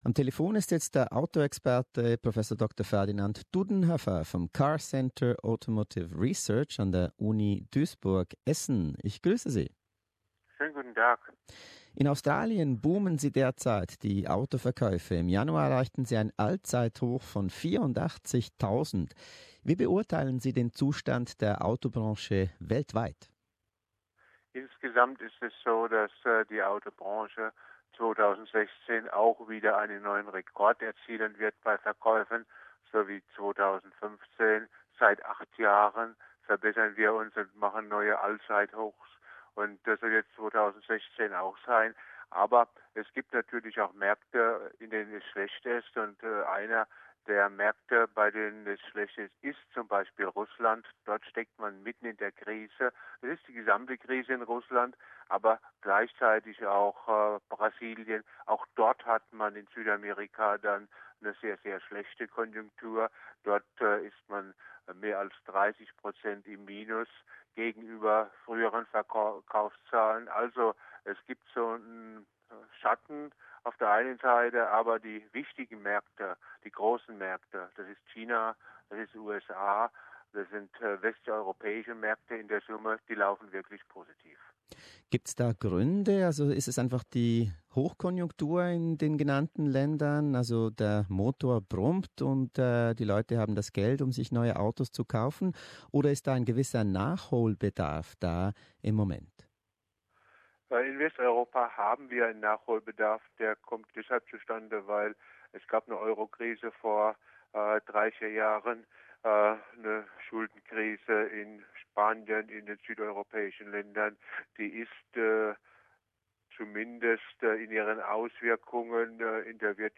Im SBS Interview äussert er sich zu den neuesten Verkaufszahlen in Australien, dem anhaltenden Boom bei den SUVs, und wie lang es noch dauern wird, bis selbstfahrende Autos herkömmliche Wagen ablösen werden.